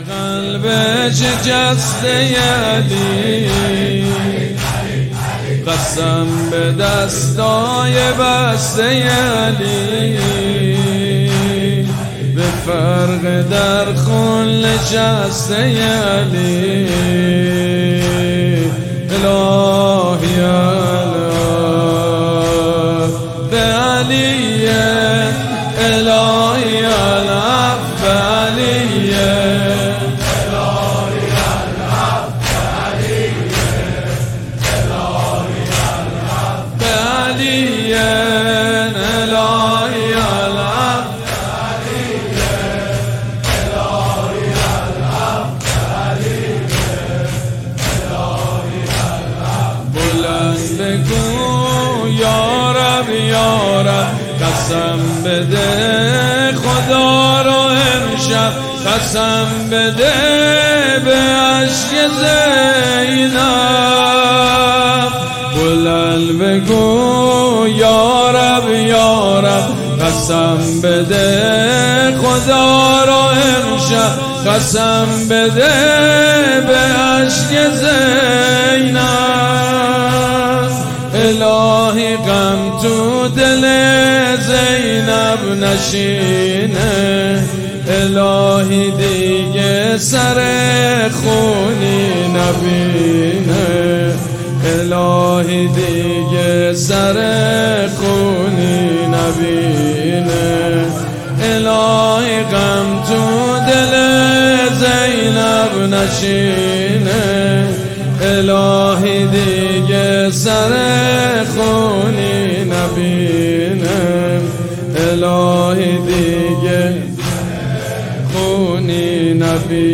حسینیه ریحانة‌الحسین (سلام‌الله‌علیها)
مداح
حاج سید مجید بنی فاطمه
شب هفتم